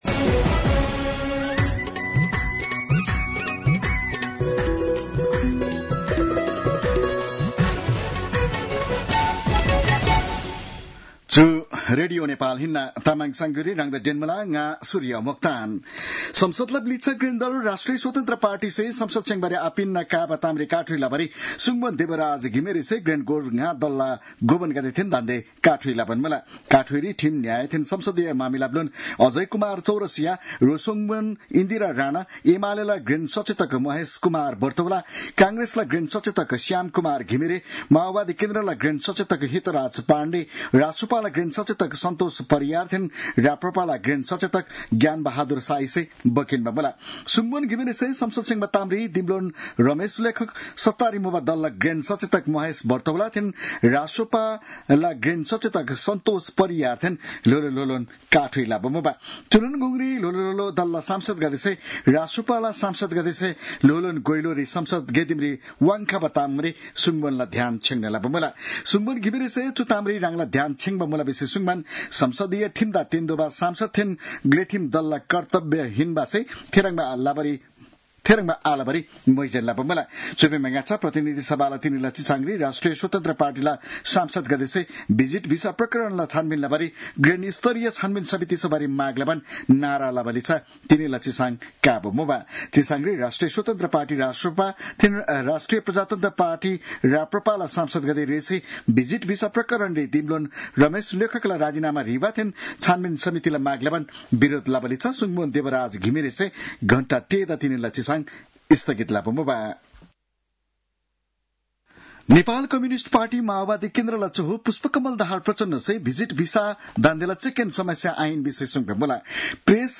तामाङ भाषाको समाचार : ३ असार , २०८२